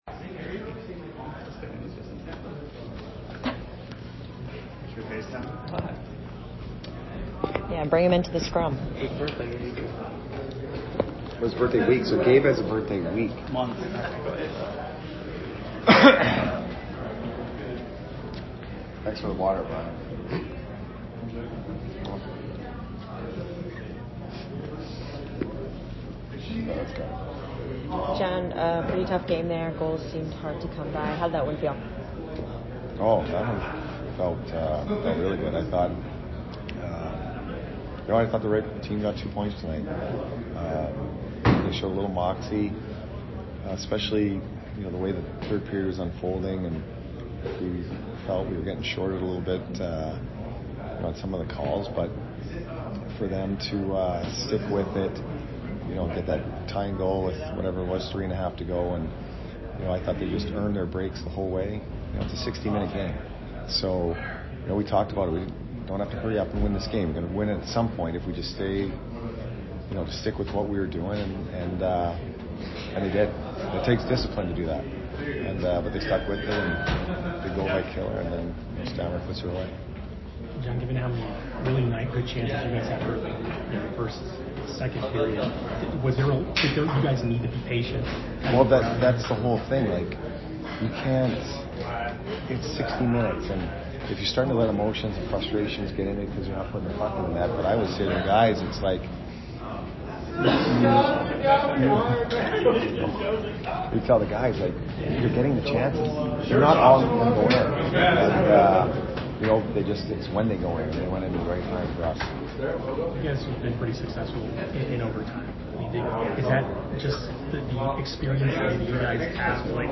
Head Coach Jon Cooper Post Game 11/19/22 @ NSH